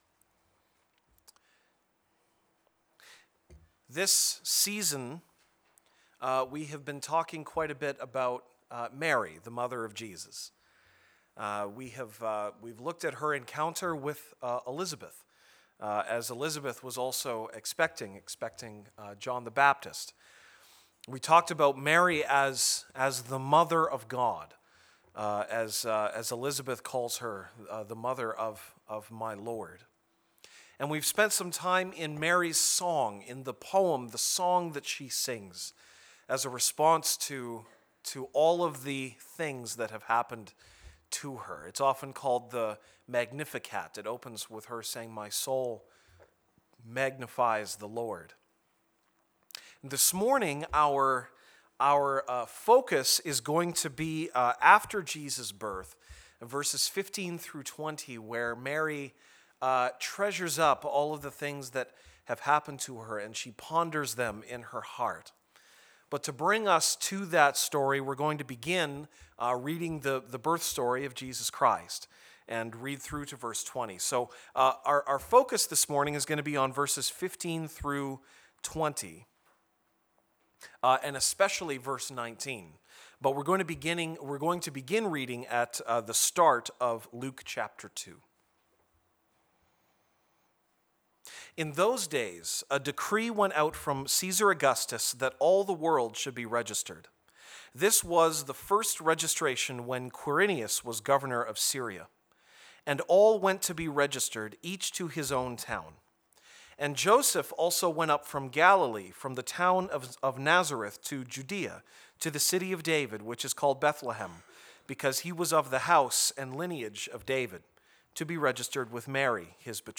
December 25, 2016 (Sunday Morning)